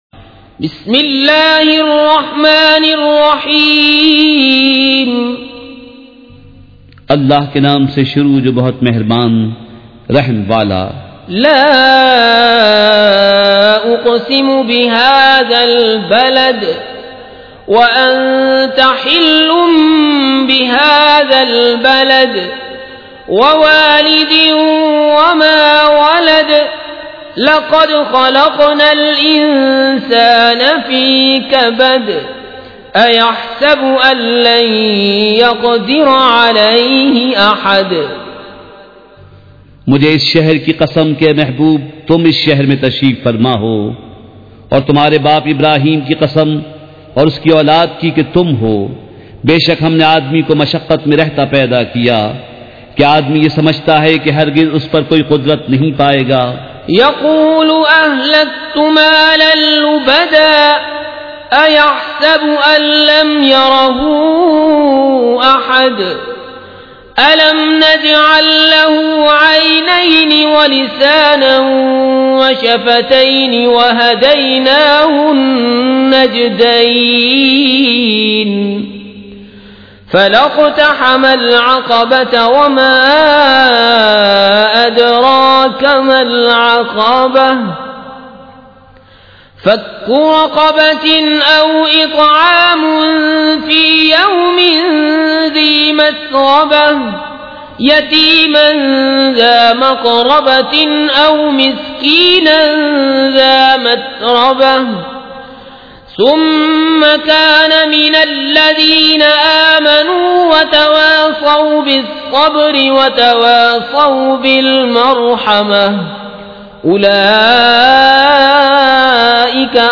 سورۃ البلد مع ترجمہ کنزالایمان ZiaeTaiba Audio میڈیا کی معلومات نام سورۃ البلد مع ترجمہ کنزالایمان موضوع تلاوت آواز دیگر زبان عربی کل نتائج 1807 قسم آڈیو ڈاؤن لوڈ MP 3 ڈاؤن لوڈ MP 4 متعلقہ تجویزوآراء